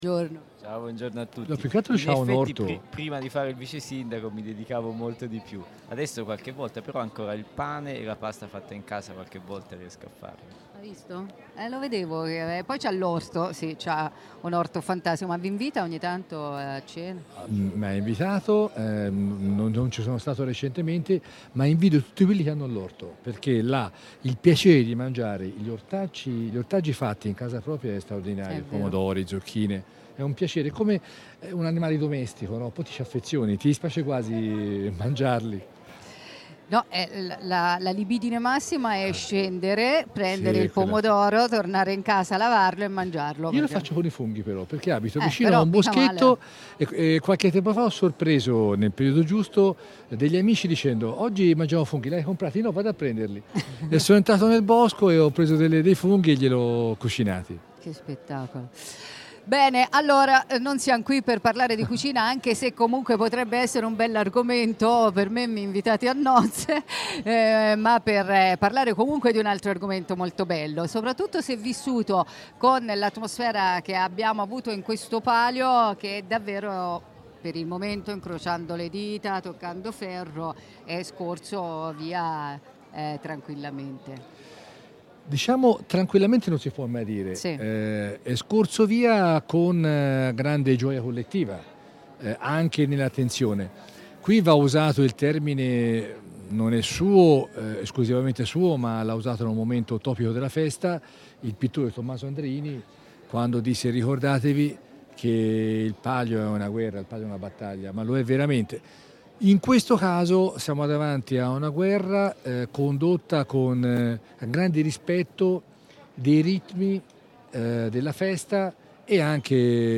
Parole del sindaco Bruno Valentini ospite della diretta dai palchi di Antenna Radio Esse